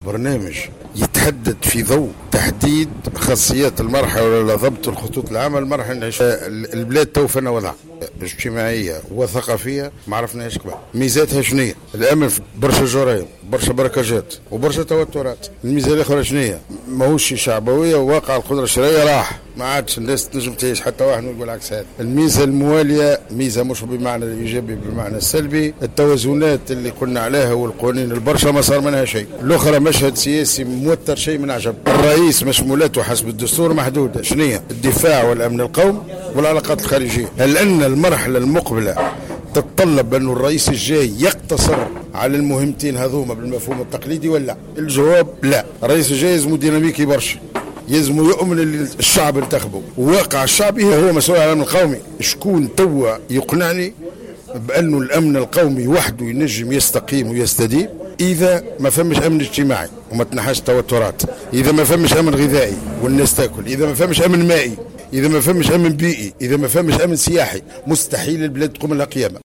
أدى المُترّشح للانتخابية الرئاسية السابقة لأوانها، عبيد البريكي اليوْم الخميس زيارة إلى بن ڨردان من ولاية مدنين.